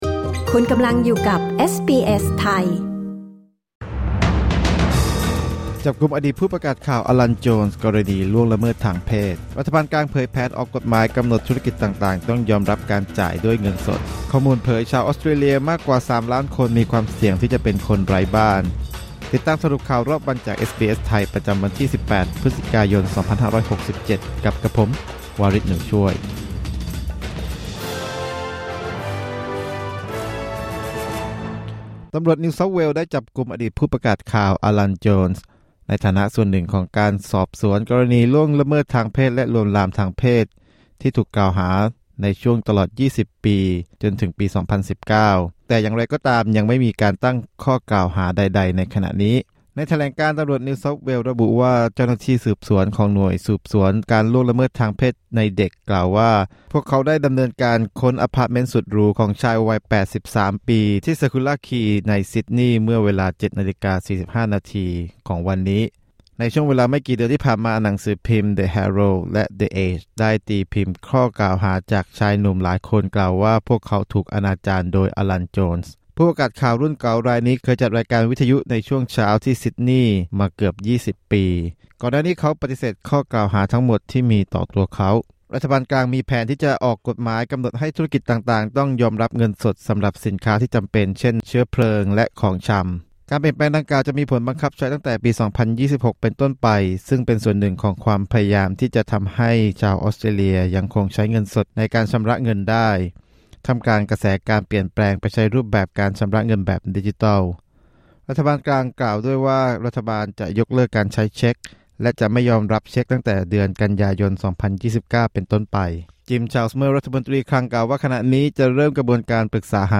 สรุปข่าวรอบวัน 18 พฤศจิกายน 2567